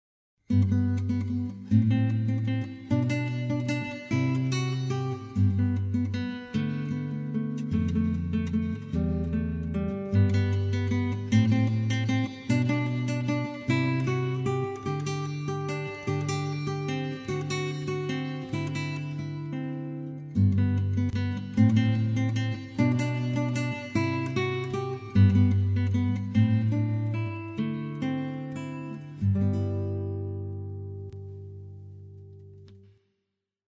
Solo gitaar
• Instrumenten: Gitaar Solo